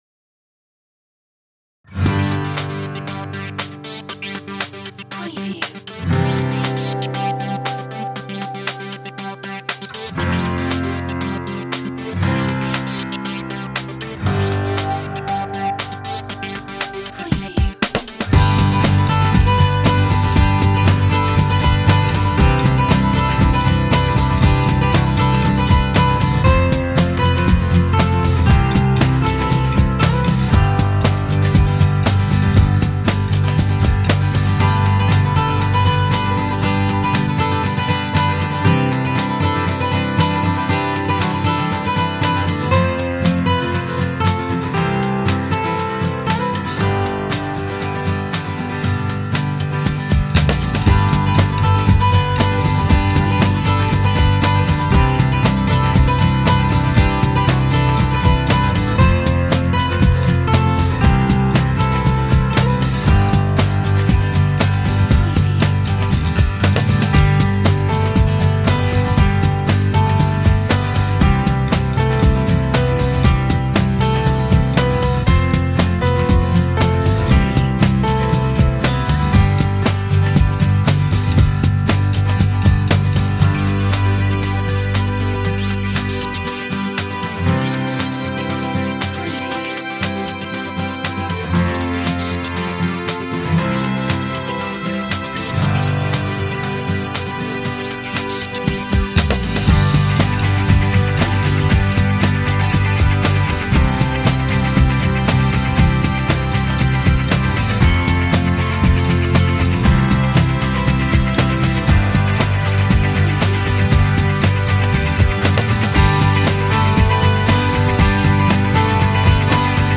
Acoustic goodness